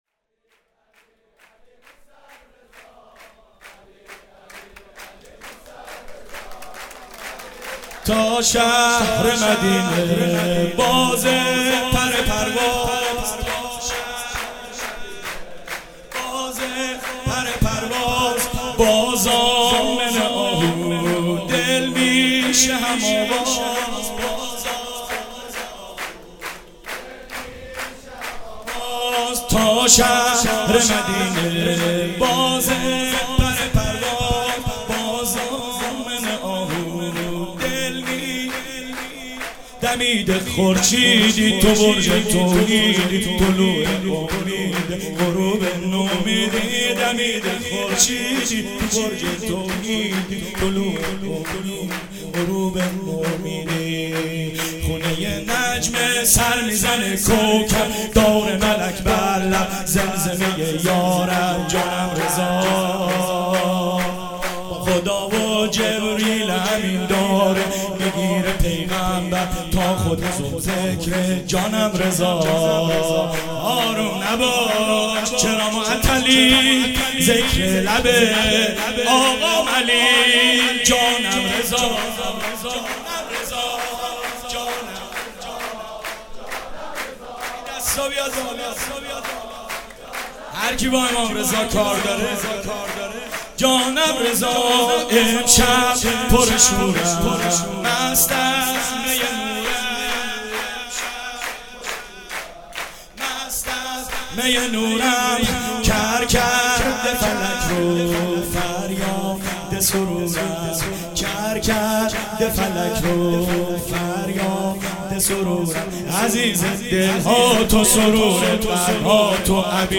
مراسم شب میلاد امام رضا(ع) 96
شور